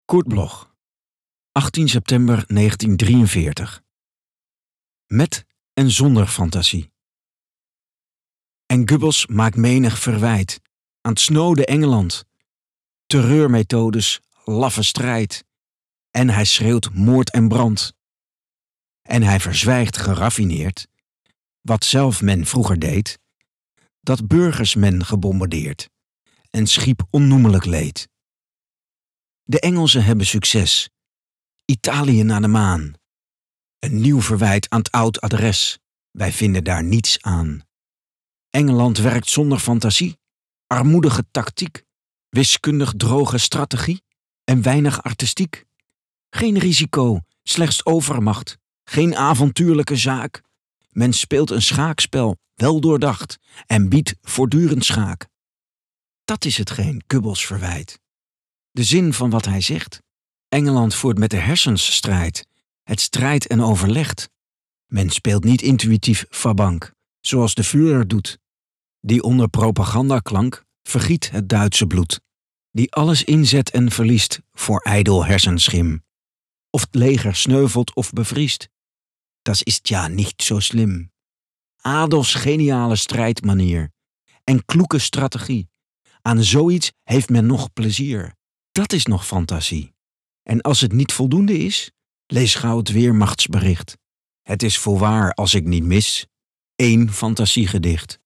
Aufnahme: MOST, Amsterdam · Bearbeitung: Kristen & Schmidt, Wiesbaden